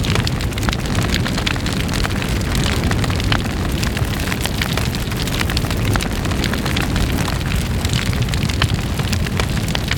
SFX_FireBig_L.wav